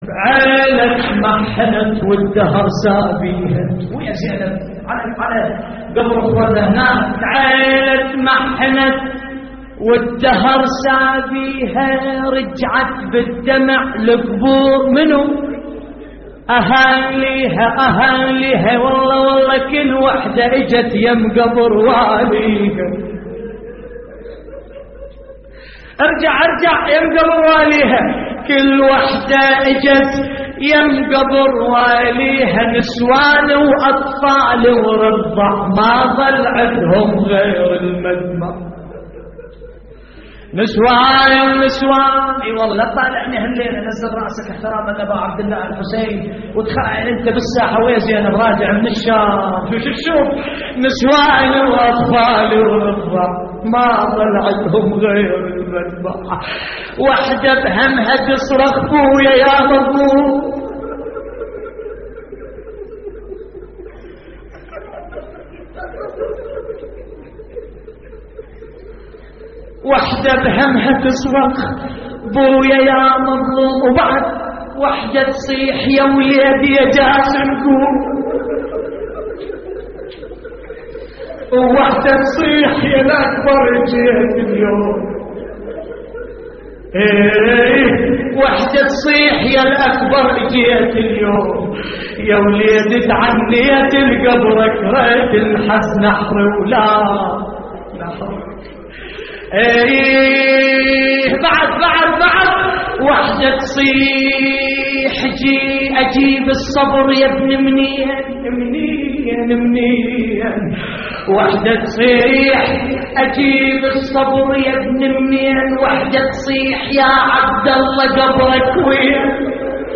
نعي